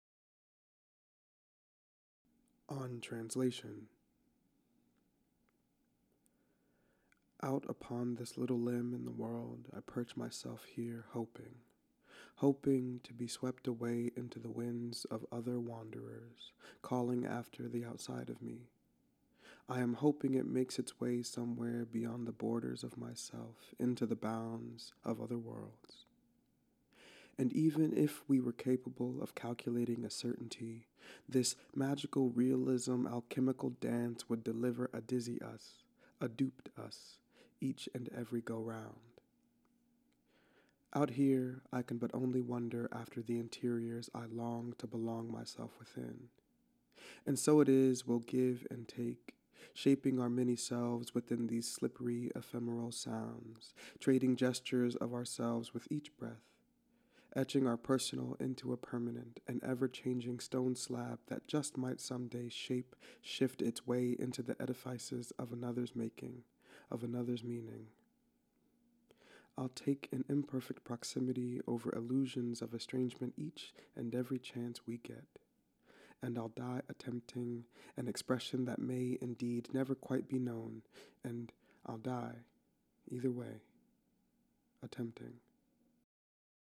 reading on translation